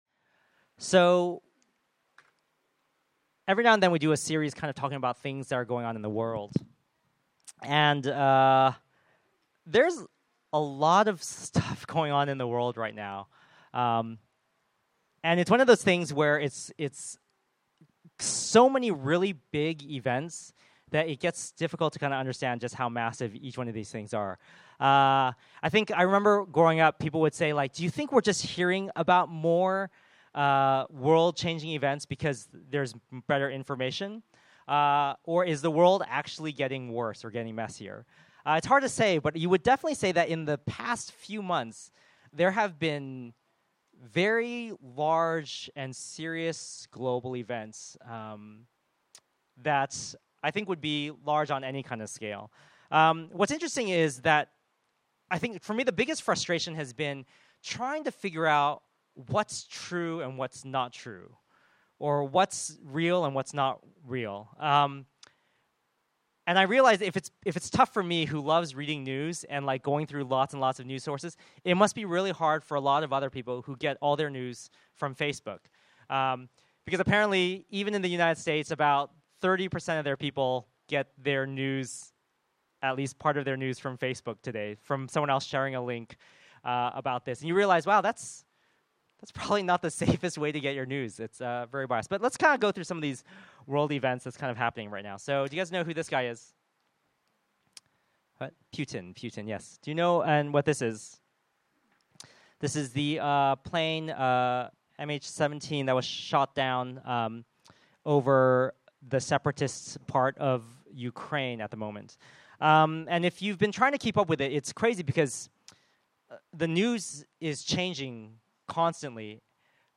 Category Sermon Audio